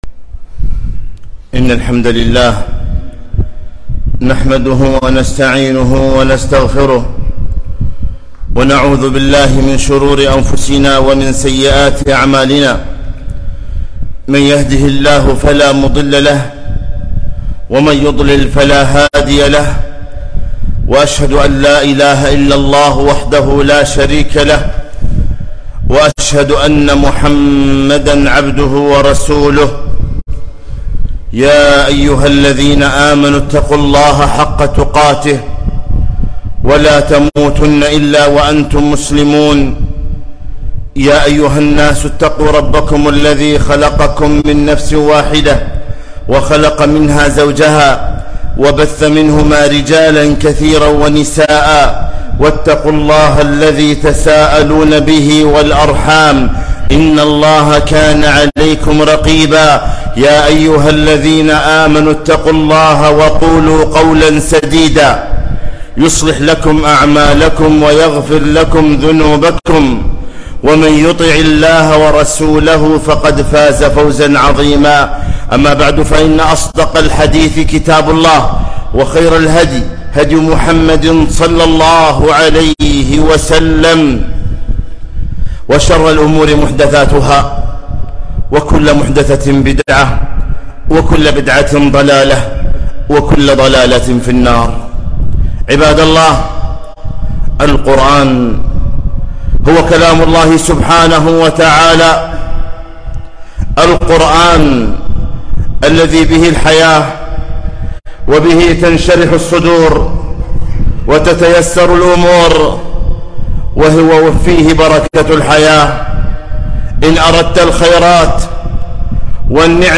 خطبة - ( فضل القرآن وأهله)